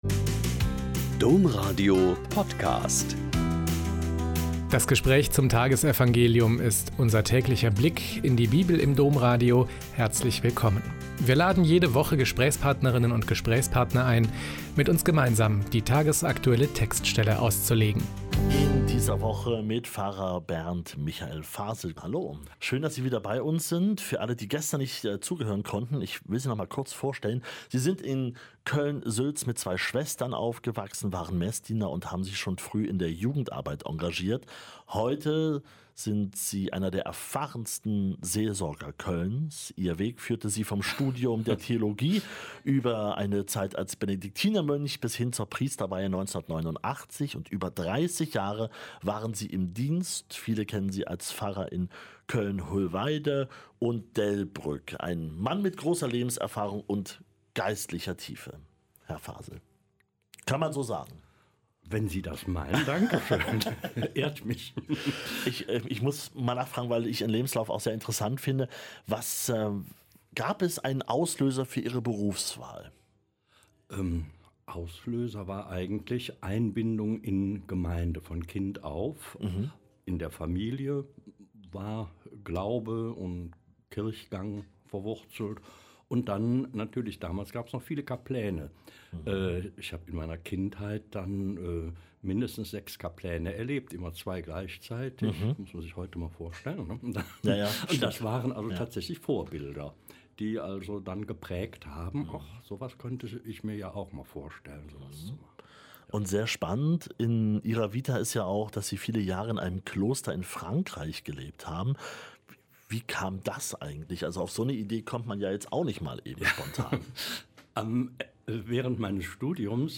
Joh 15,18-21 - Gespräch